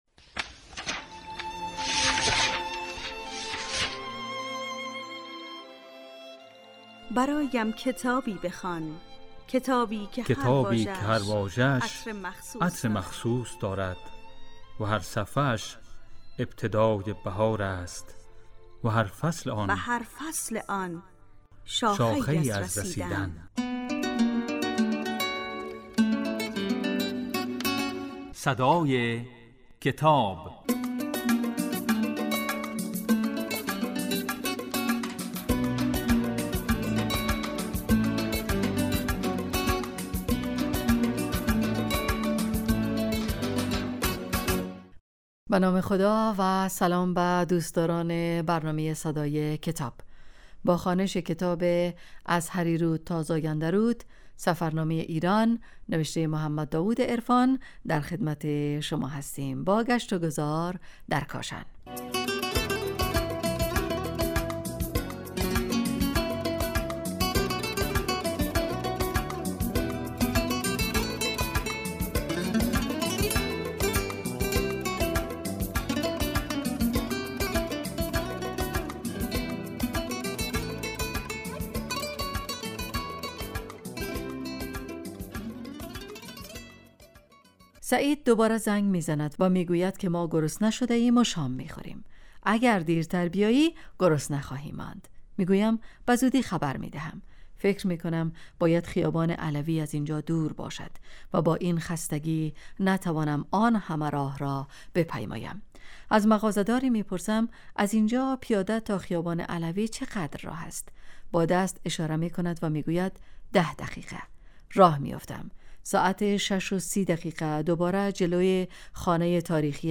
این برنامه کتاب صوتی است و در روزهای یکشنبه، سه شنبه و پنج شنبه در بخش صبحگاهی پخش و در بخش نیمروزی بازپخش می شود.